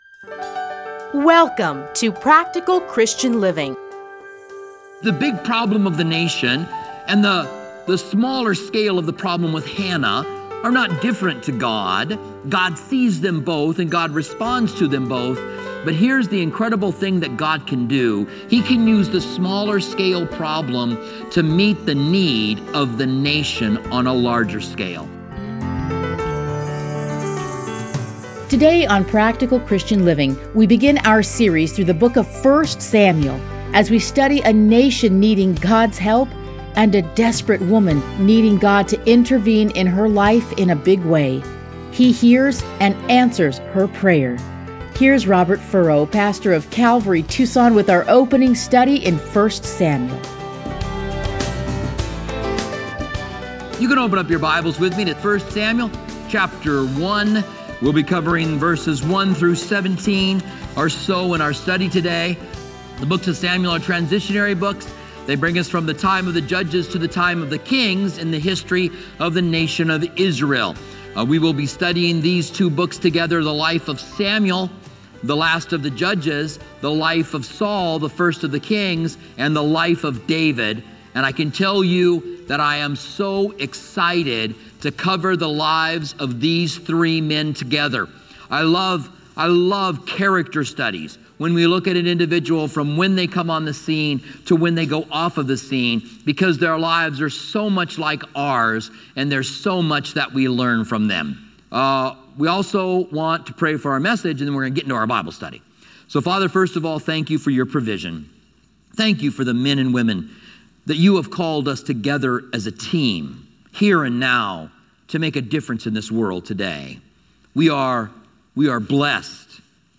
Listen to a teaching from 1 Samuel 1:1-17.